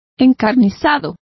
Complete with pronunciation of the translation of bitter.